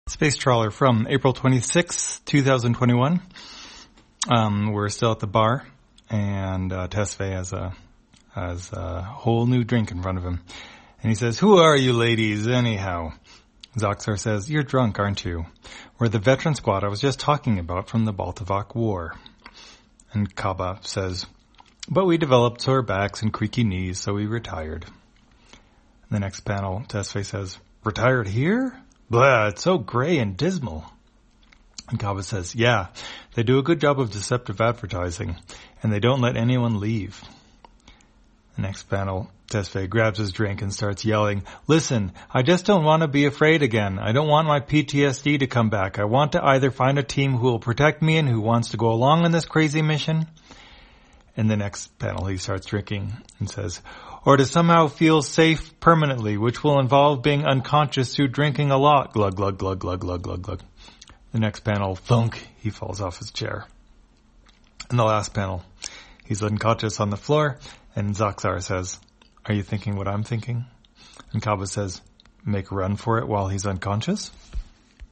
Spacetrawler, audio version For the blind or visually impaired, April 26, 2021.